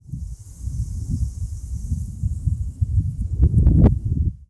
455風,登山,山頂,暴風,強風,自然,
効果音自然野外